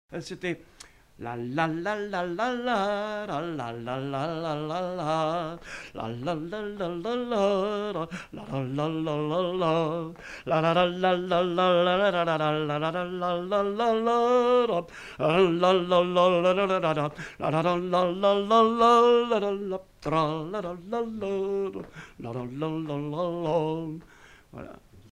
Aire culturelle : Haut-Agenais
Genre : chant
Effectif : 1
Type de voix : voix d'homme
Production du son : fredonné
Danse : rondeau